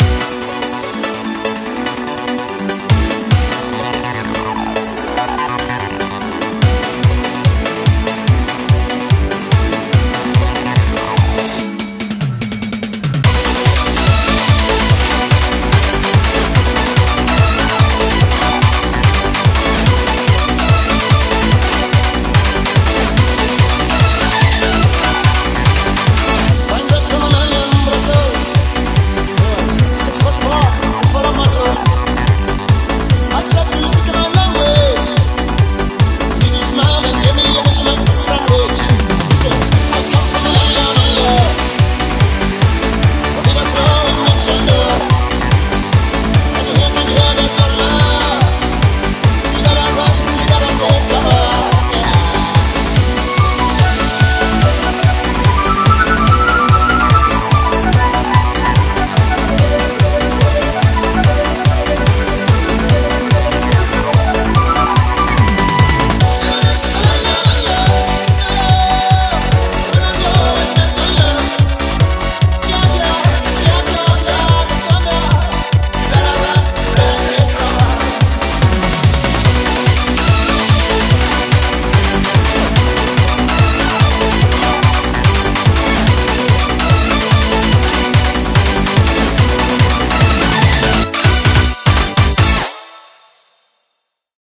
BPM145
Audio QualityPerfect (High Quality)
Comments[AUSSIE EUROBEAT]
Song type: Licensed remix